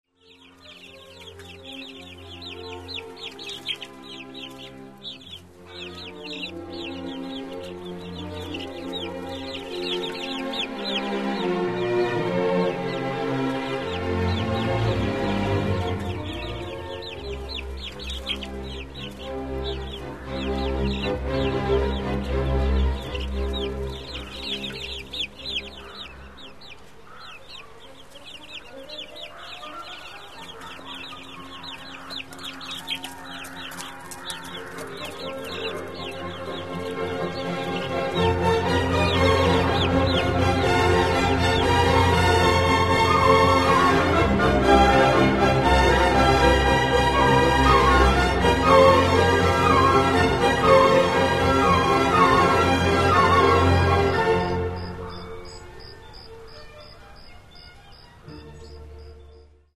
Каталог -> Другое -> Relax-piano, музыкальная терапия
Mp3- I. Allegro ma non troppo (звуки села)